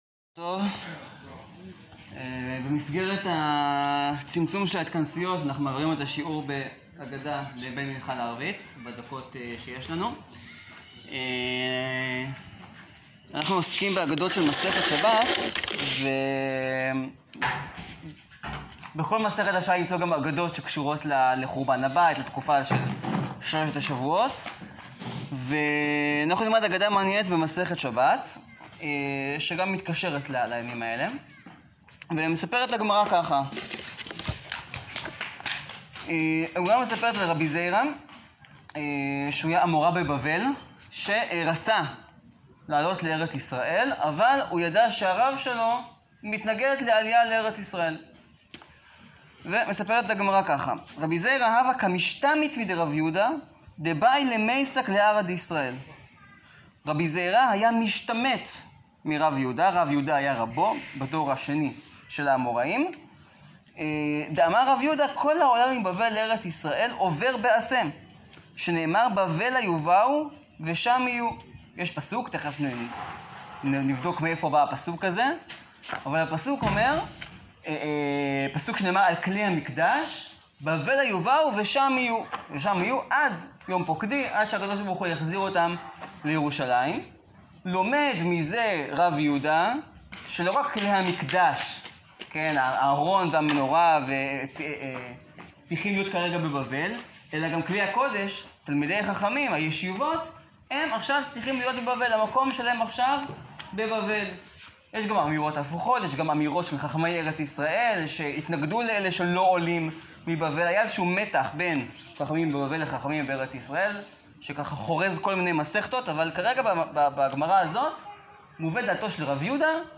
שיעור קצר באגדה לימי בין המצרים – מה טלטל את רבי זירא לפני עלייתו המחתרתית לארץ ישראל, ומה מקבלים דווקא מהגלות הבבל?